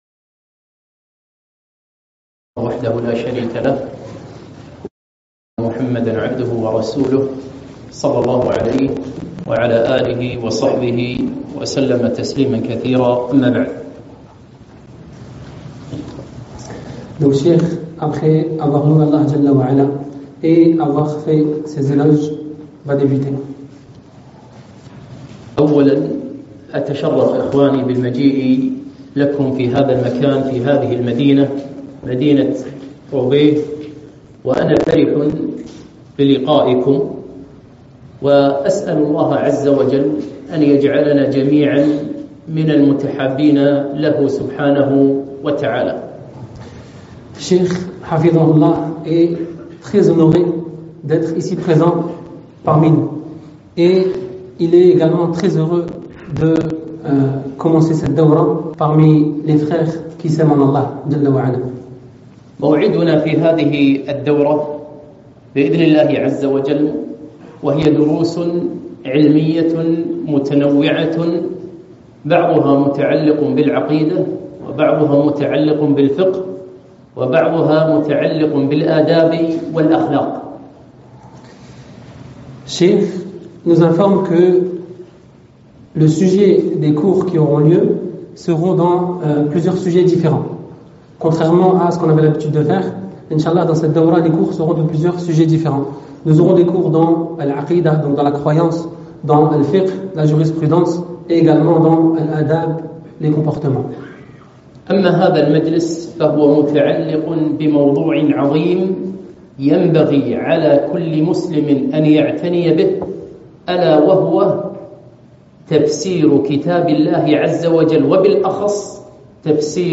ألقيت يوم الجمعة 3-5-2024 م في فرنسا